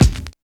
34 KICK 2.wav